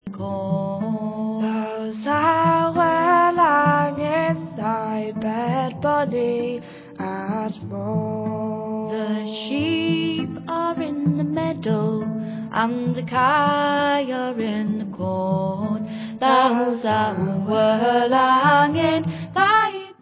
sledovat novinky v oddělení Folk